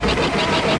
Falcon-Guns